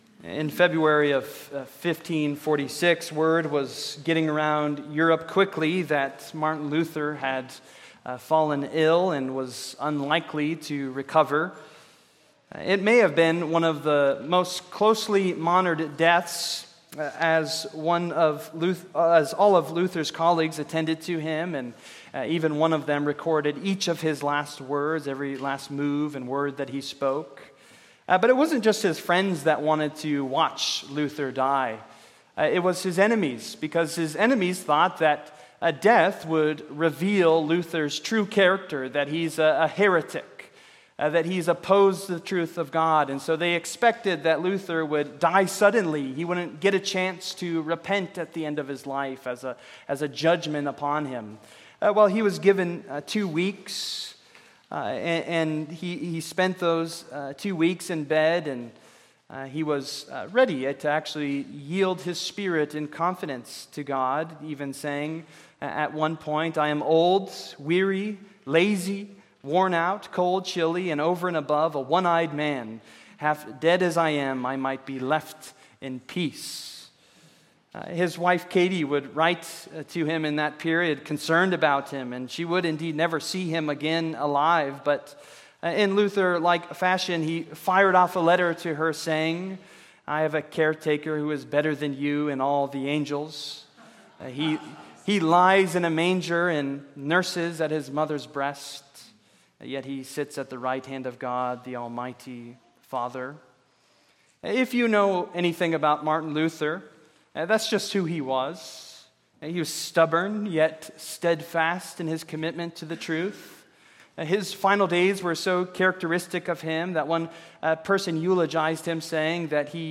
Service: Sunday Morning